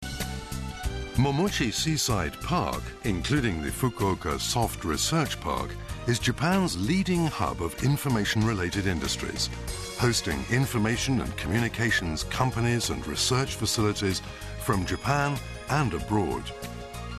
注：音声データは、DVD「DISCOVER Fukuoka City」の英語リスニング教材